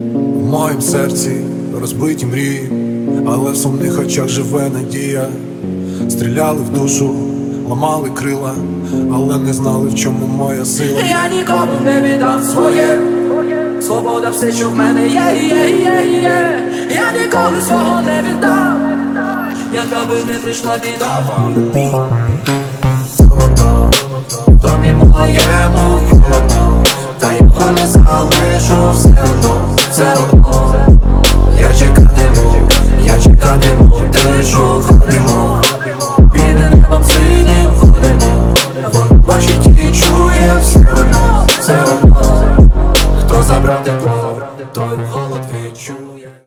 • Качество: 320, Stereo
Trap
українська музика